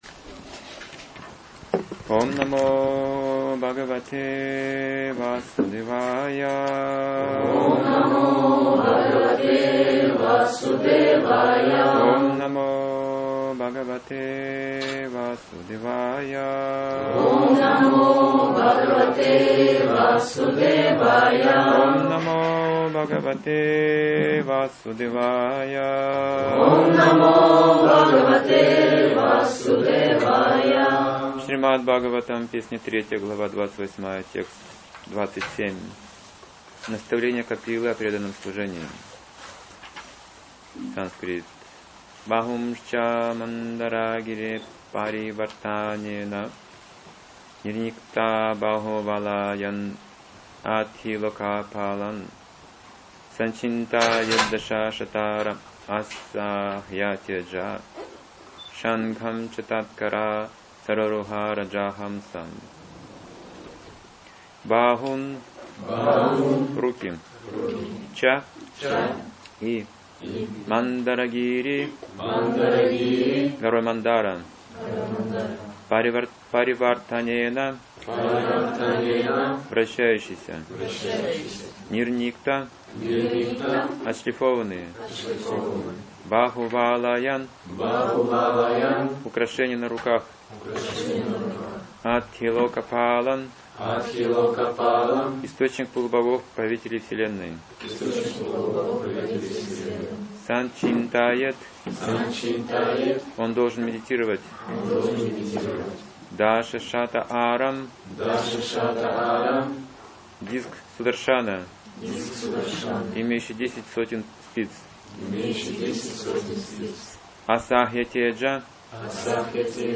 Темы, затронутые в лекции: